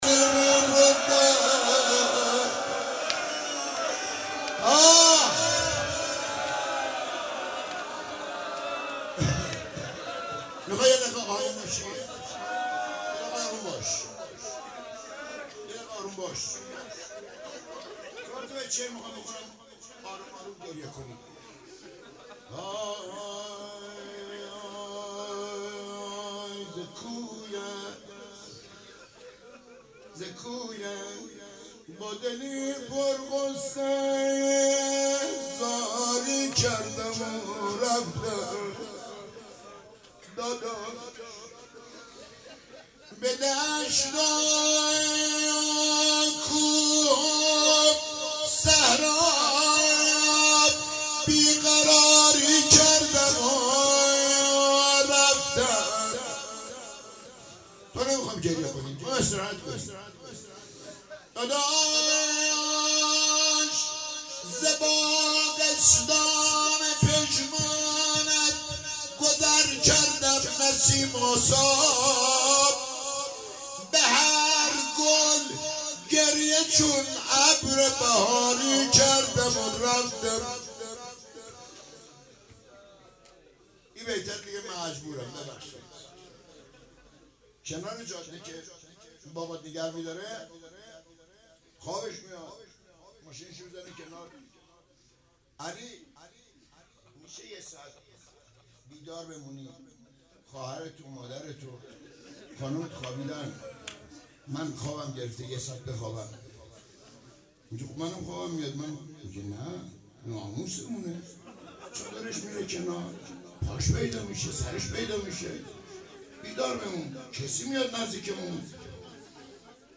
اربعین - روضه - ز کویت با دلی پر غصه